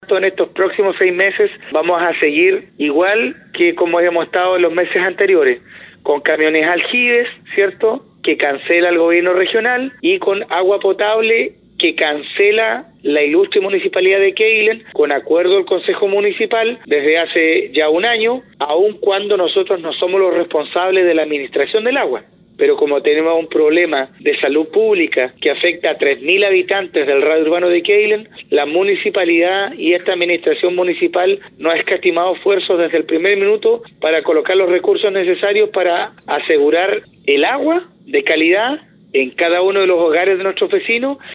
El edil aclaró que actualmente el agua que se entrega vía camiones aljibes, es pagada por la municipalidad, por la responsabilidad que le cabe en el tema sanitario.
05-ALCALDE-QUEILEN-2.mp3